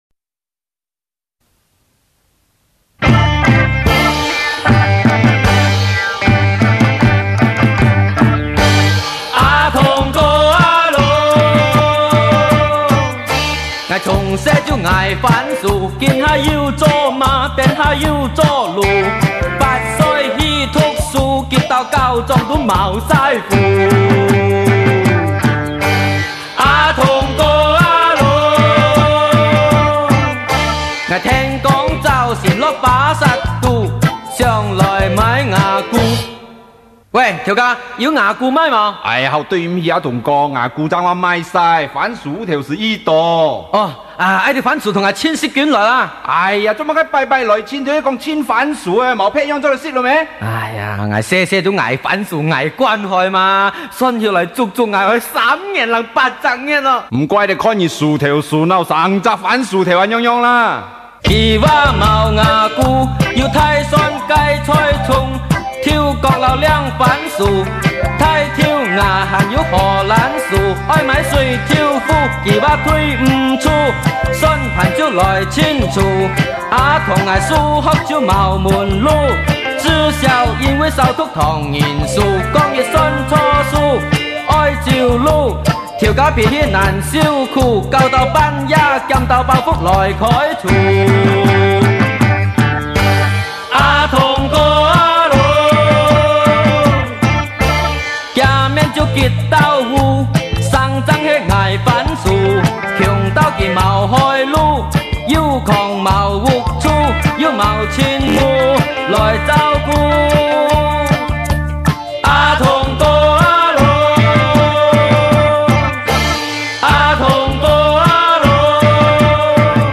客家流行歌曲